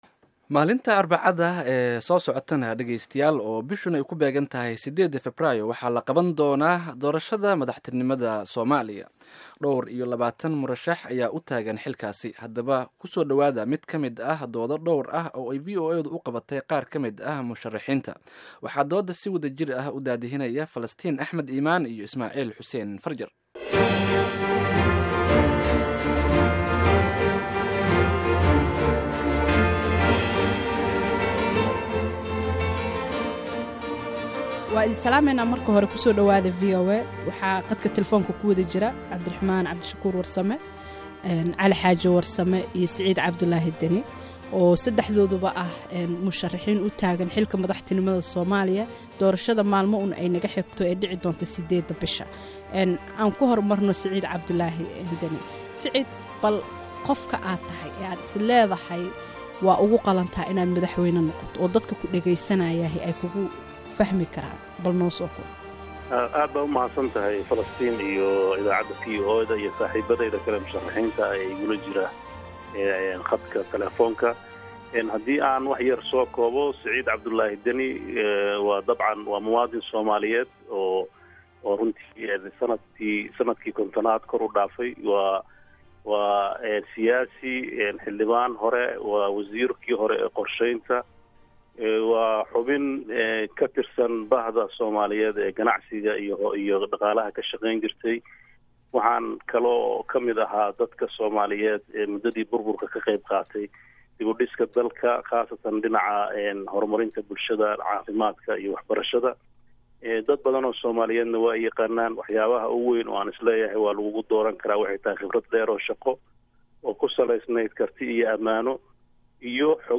VOA-da ayaa u qabatay qaar ka mid ah musharaxiinta dood ay barnaamijka iyo siyaasadda ay ugu talagaleen Soomaaliya wax looga weydiinayo.